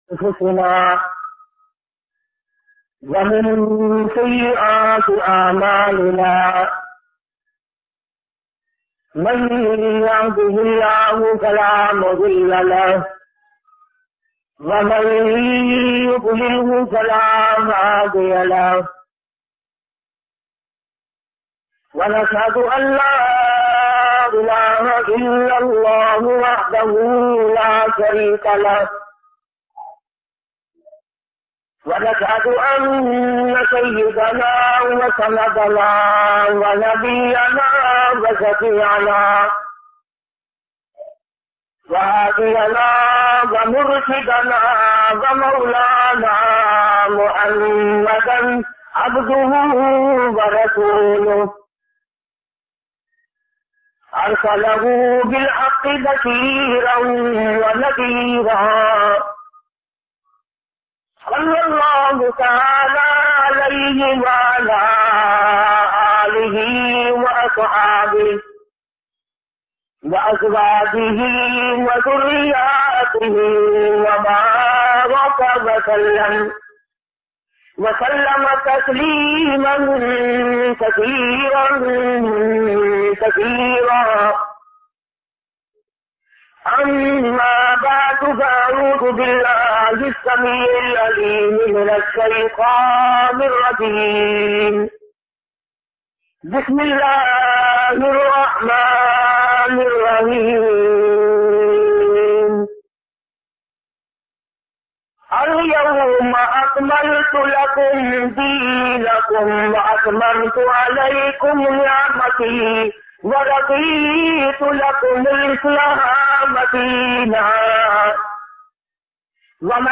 Hazrat Zakaria bayan MP3